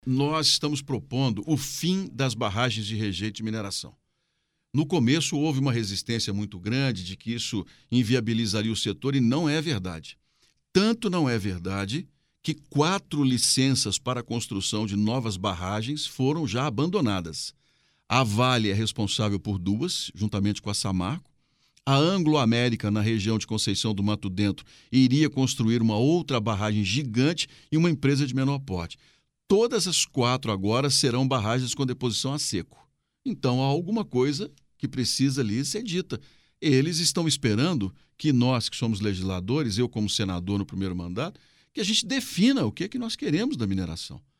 senador Carlos Viana